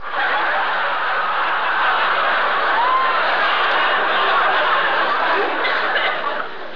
Download Laugh Track sound effect for free.
Laugh Track